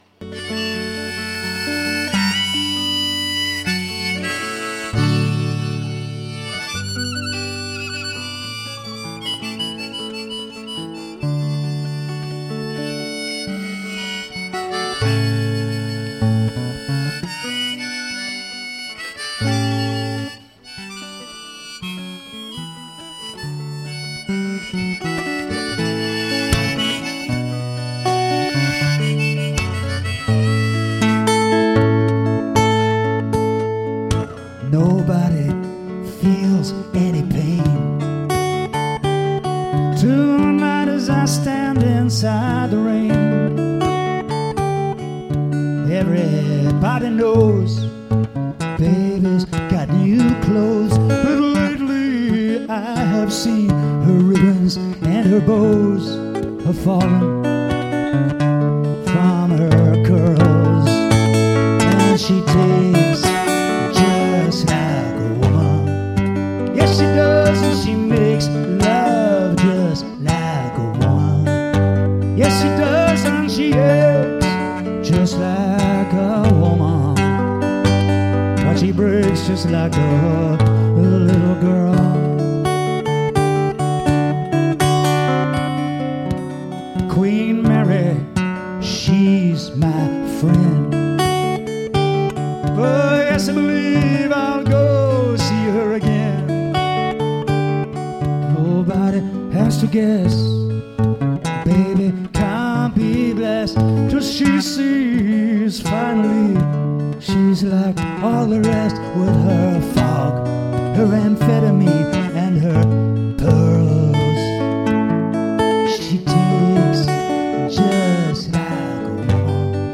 Live at Peregian Beach Hotel 23/4/2016